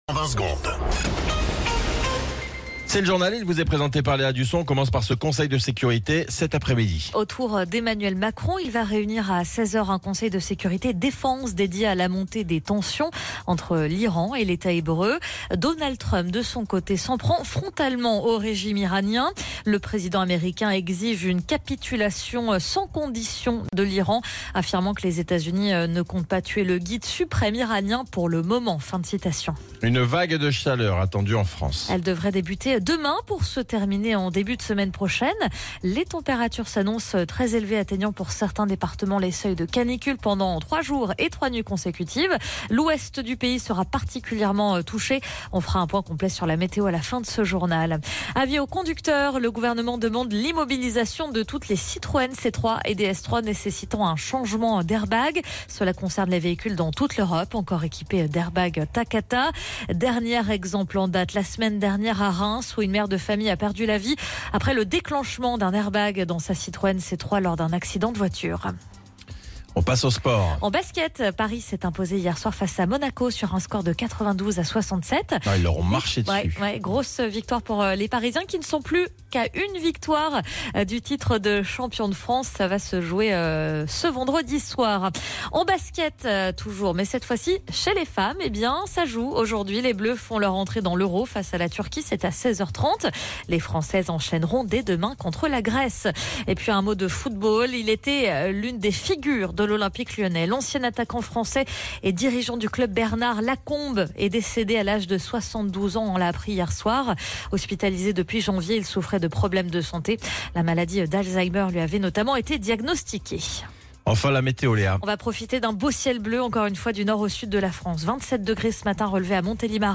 Flash Info National 18 Juin 2025 Du 18/06/2025 à 07h10 .